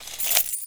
Key Chain Zip Sound
household